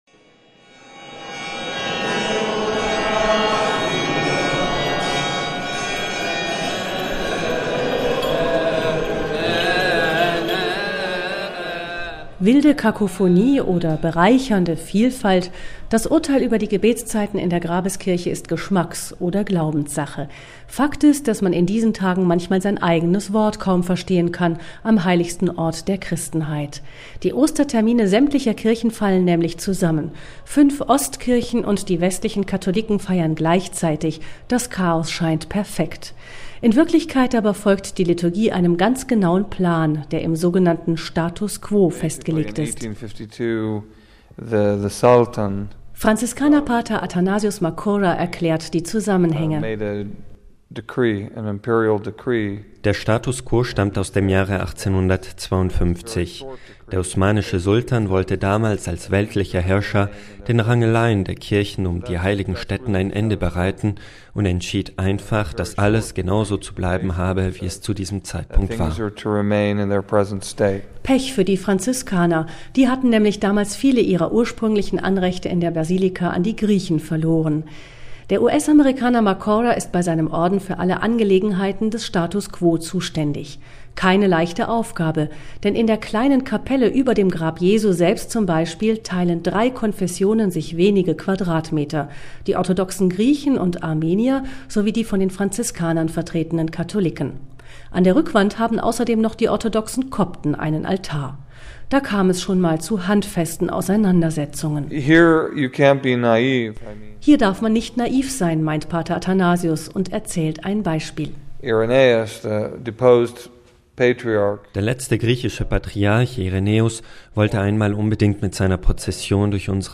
Wilde Kakophonie oder bereichernde Vielfalt: Das Urteil über die Gebetszeiten in der Grabeskirche ist Geschmacks- oder Glaubenssache. Fakt ist, dass man in diesen Tagen manchmal sein eigenes Wort kaum verstehen kann, am heiligsten Ort der Christenheit.